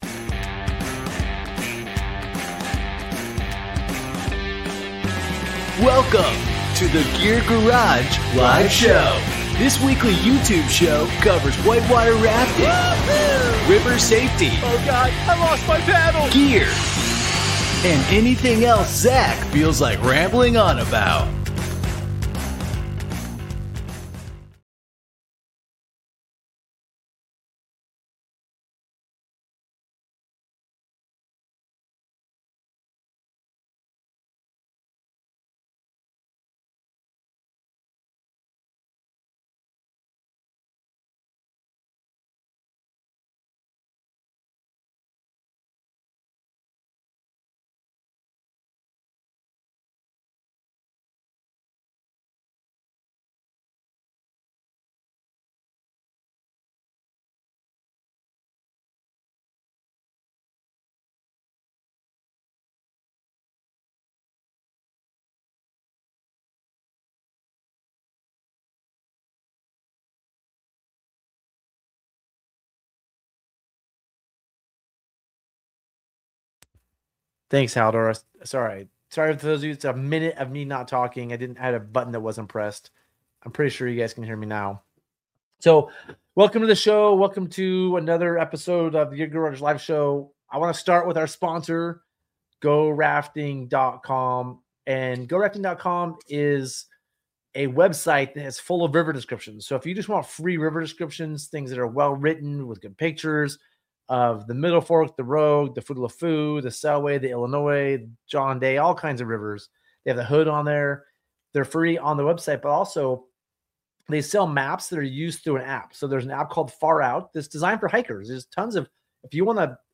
Knots, Gear & Oar Length | Gear Garage Live Show